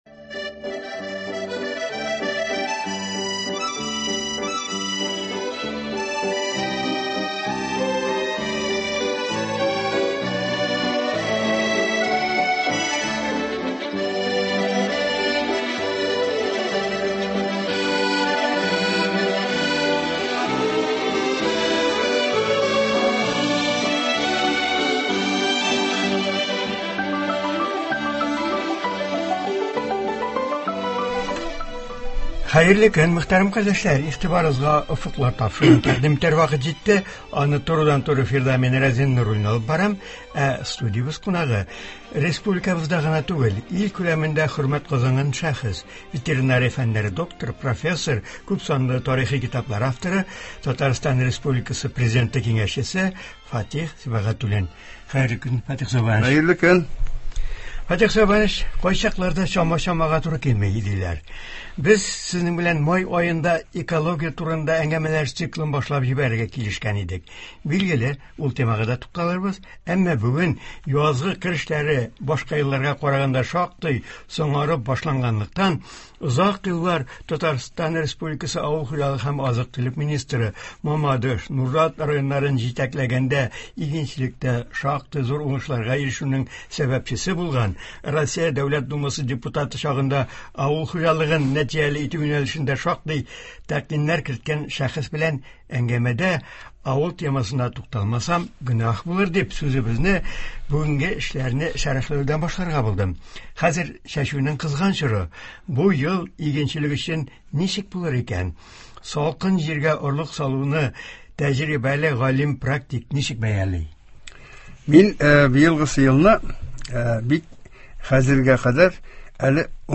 Ветеринария фәннәре докторы, профессор, Татарстан Президенты киңәшчесе Фатих Сибагатуллин узган тапшыруда республикабызда булдырылачак туристлык маршрутлары турында сөйли башлаган иде. Чираттагы тапшыруда турыдан-туры эфирда шушы темага әңгәмә дәвам итә.